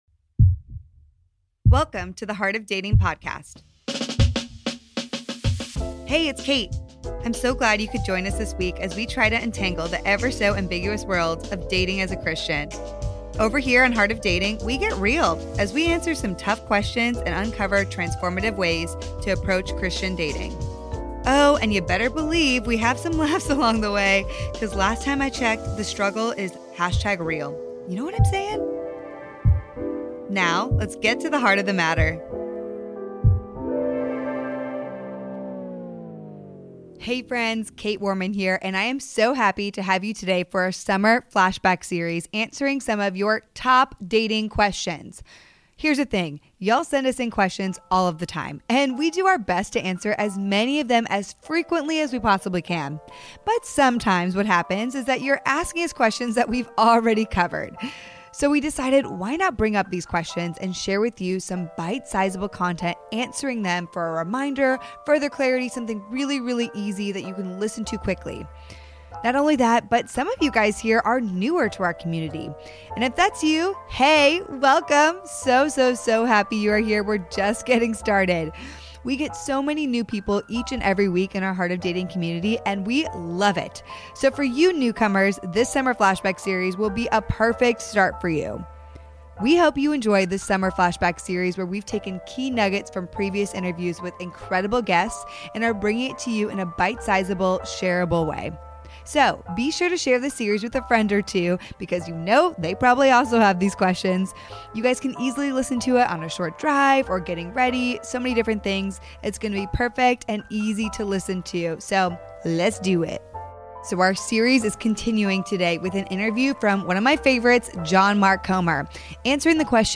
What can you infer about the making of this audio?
Though this is one of our favorite interviews to date, we apologize in advance that at times our audio got a bit spotty.